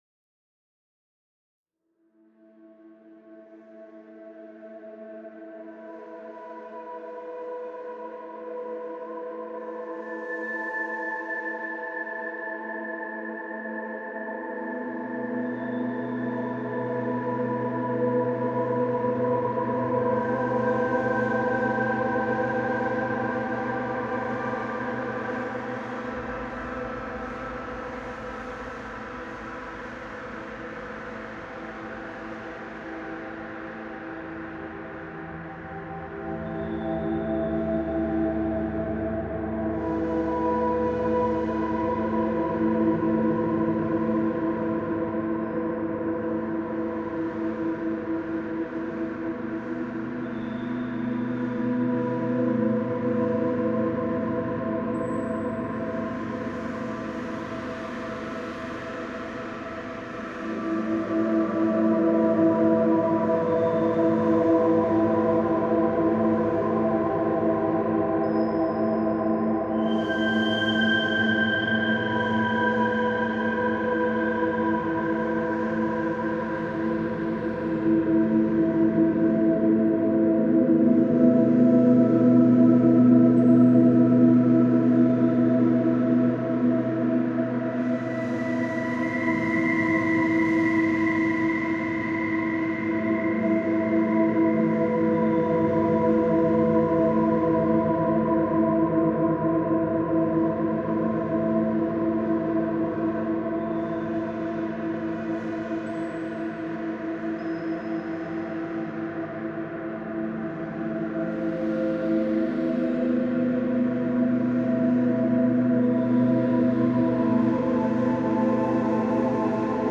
downbeat style